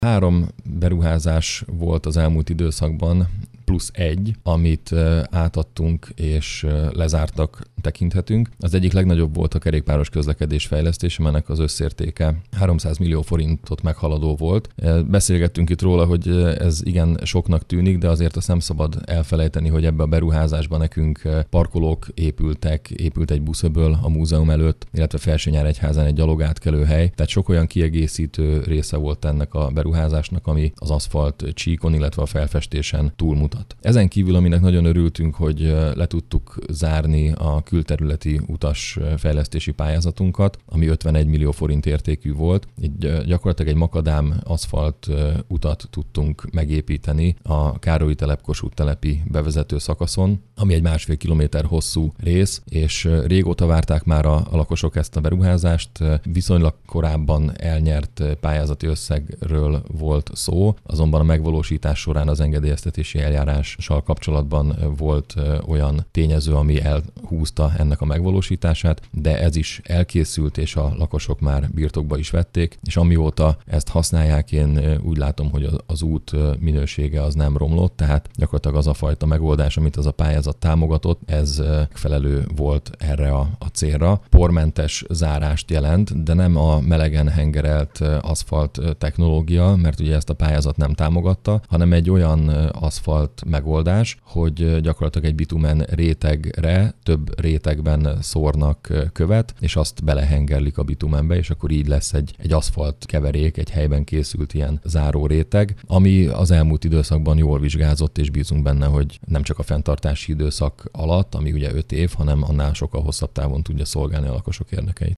Mészáros Sándor polgármester számolt be arról, hogyan fejlődött az úthálózat az utóbbi időben.